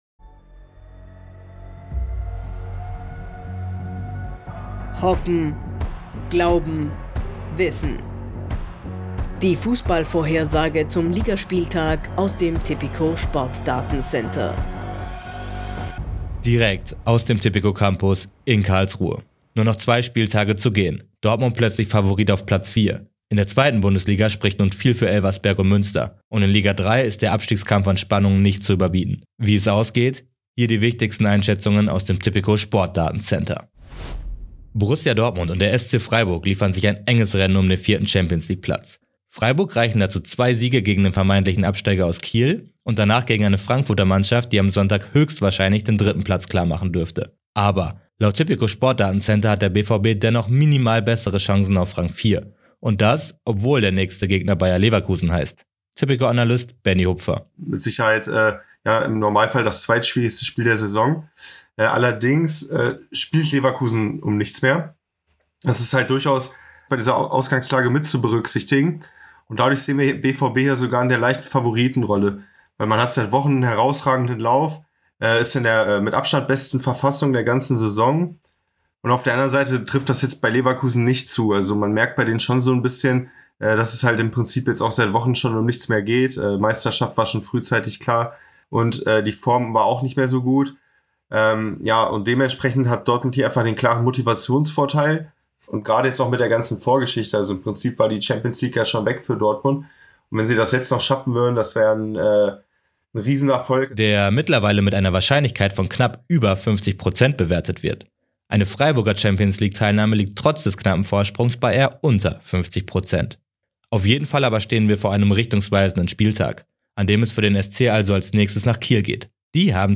Newscast Die Pressemeldung im Audio-Newscast: Hoffen – Glauben – […]